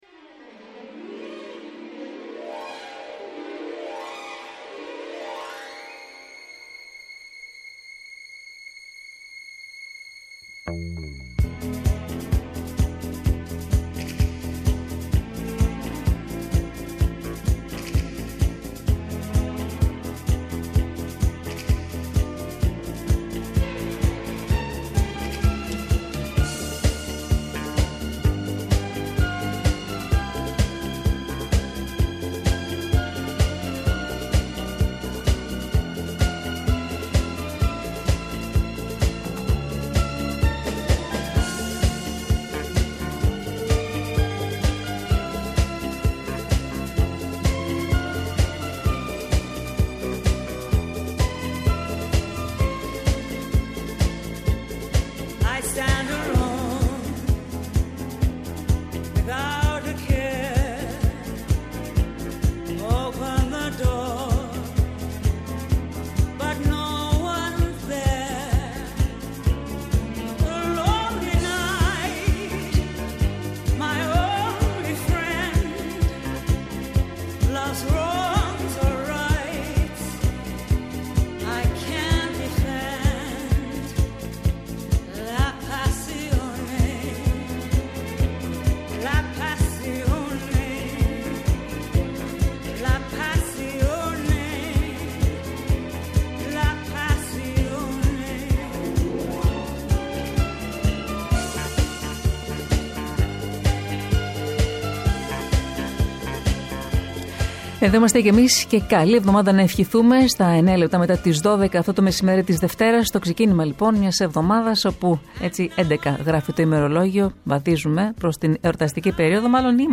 Καλεσμένος σήμερα στην εκπομπή